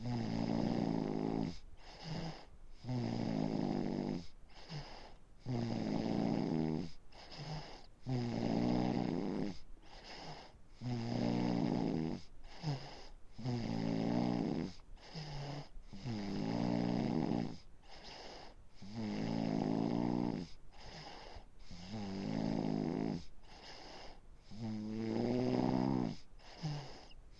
Звуки детского храпа
Ночной звук храпящего ребенка